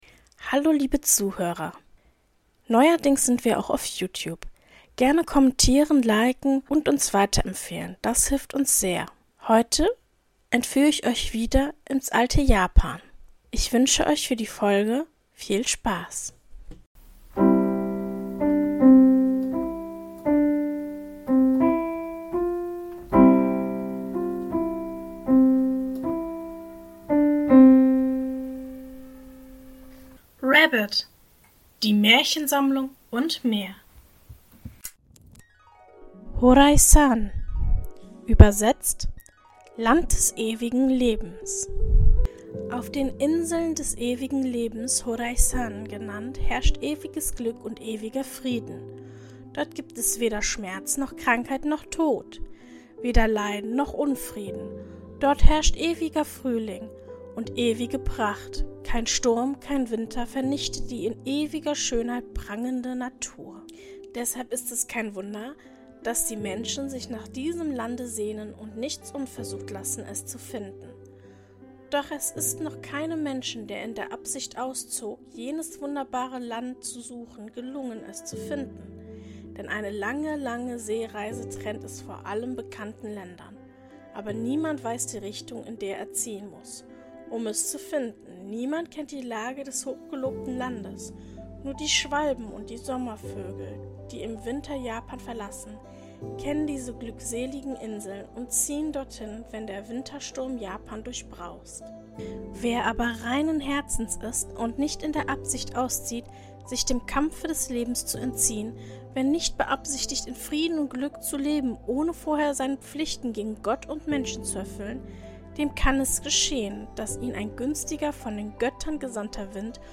In der heutigen Folge lese ich Folgendes vor: 1. Horaisan. 2.